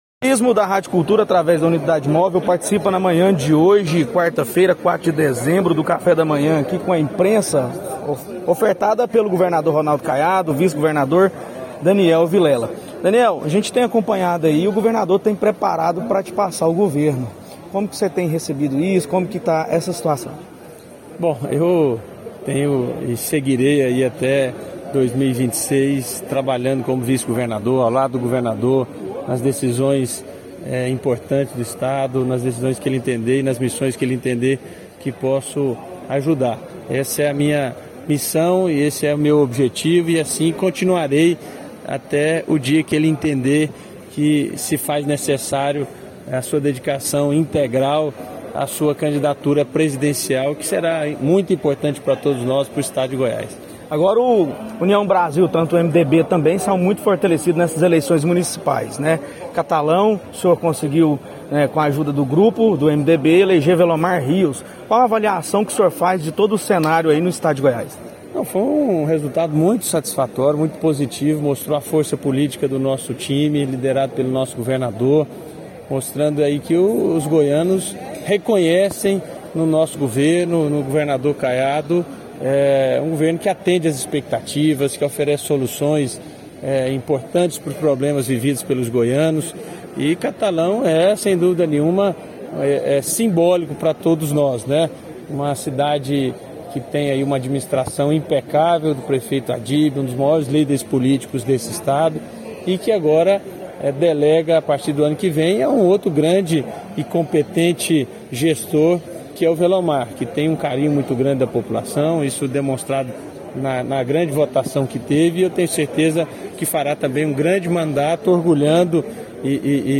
Confira a entrevista com o Subsecretário de Comunicação do Governo de Goiás, Bruno Rocha Lima: